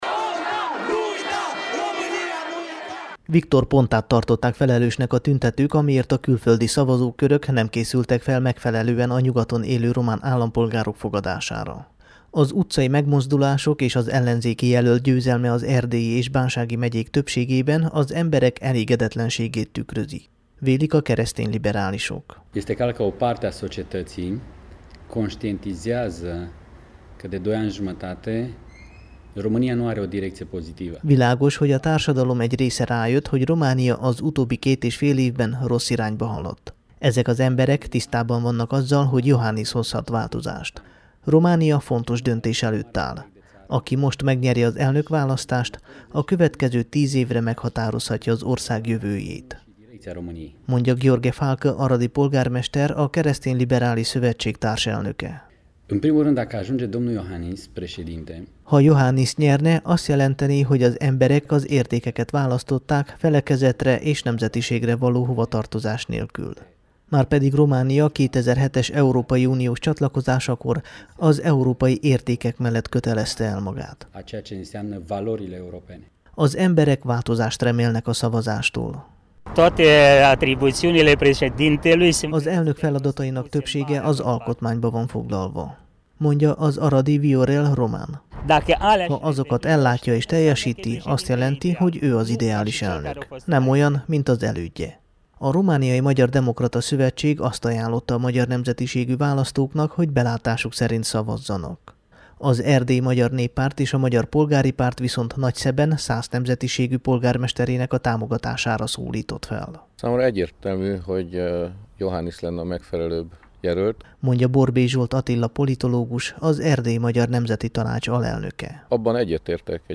riportot